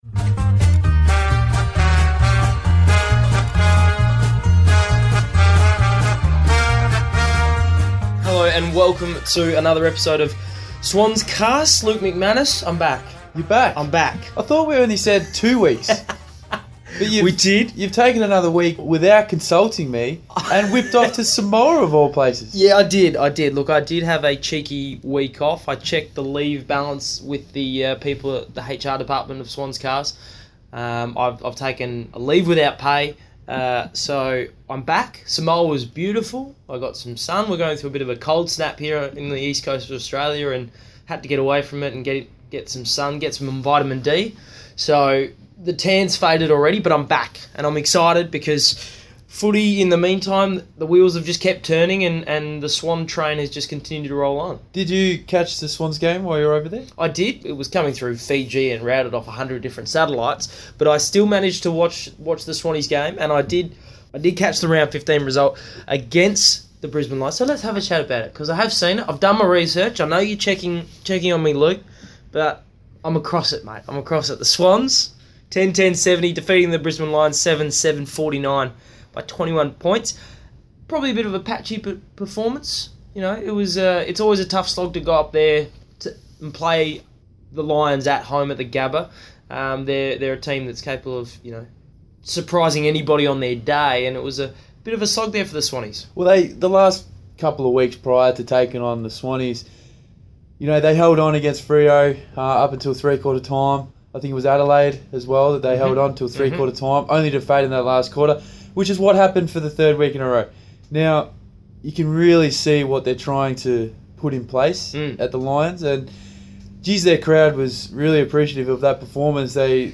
Ruckman Toby Nankervis is the special guest on the latest episode of SwansCast.